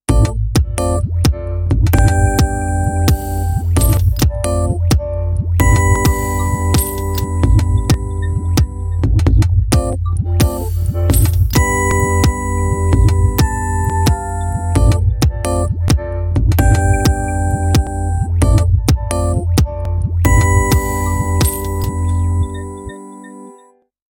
nokia-lumia-warped_24557.mp3